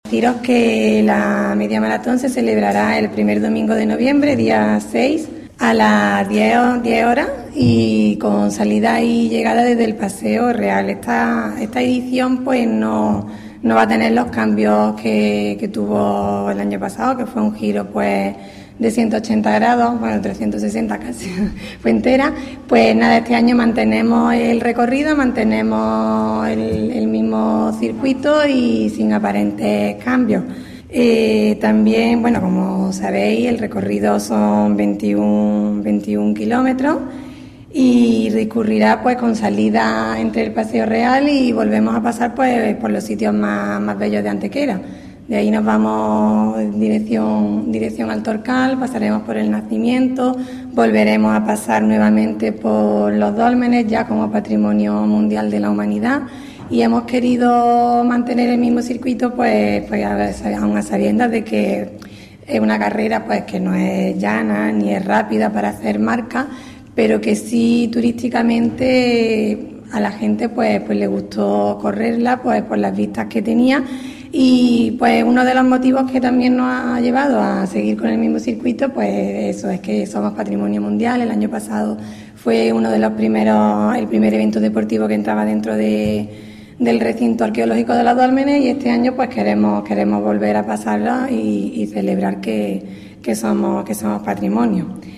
La concejal de Deportes del Ayuntamiento de Antequera, Eugenia Galán, ha confirmado hoy en rueda de prensa la apertura del plazo de inscripción para participar en la V Media Maratón Ciudad de Antequera, que un año más contará con la colaboración y patrocinio del restaurante McDonald´s y de Horno El Antequerano.
Cortes de voz